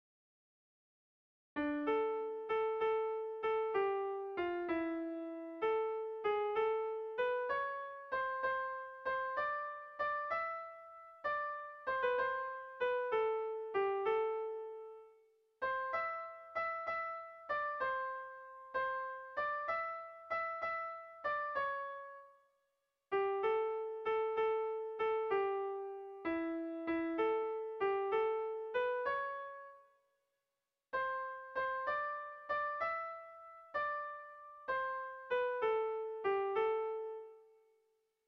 Irrizkoa
Neurrian baditu gorabeherak.
Hamarreko txikia (hg) / Bost puntuko txikia (ip)
ABDAB